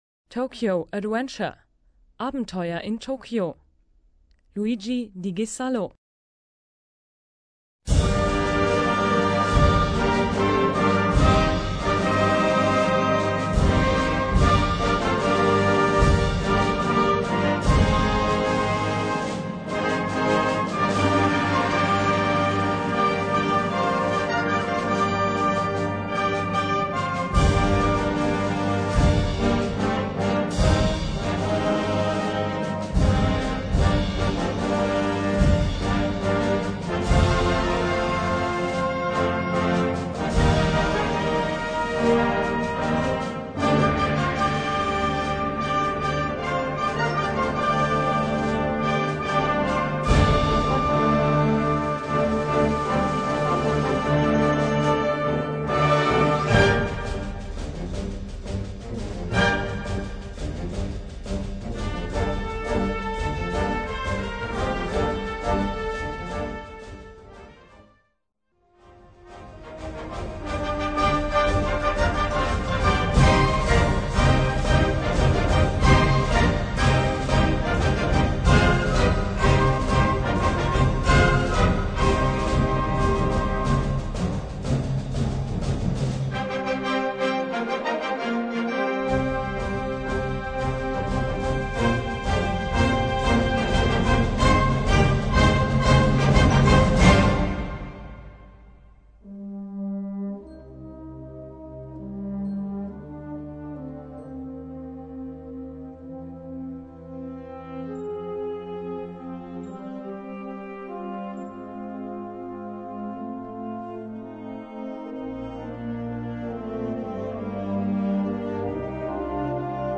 Gattung: Konzertstück
Besetzung: Blasorchester
Ein Abenteuer also mit vielen musikalischen Facetten.